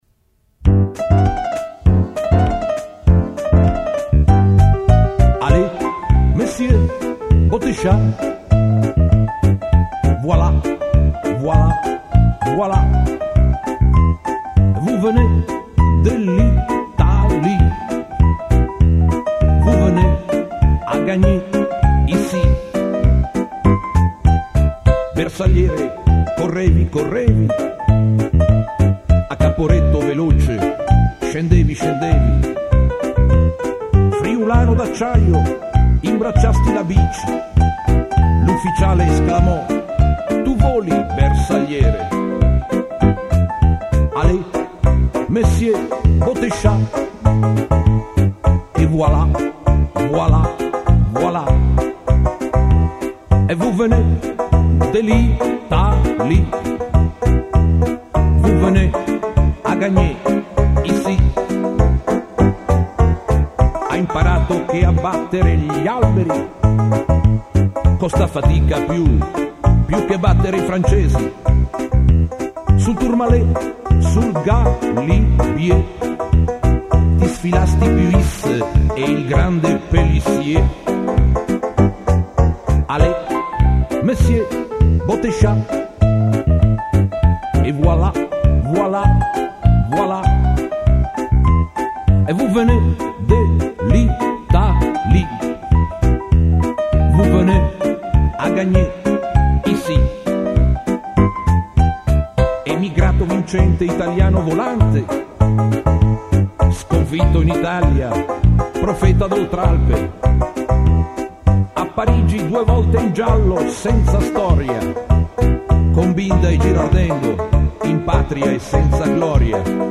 nella sala di registrazione